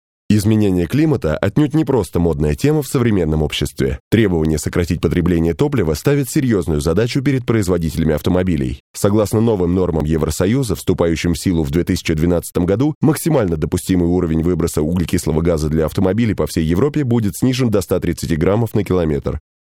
Language - Russian, Timbre - a baritone. Russian voice-over.
Sprechprobe: Sonstiges (Muttersprache):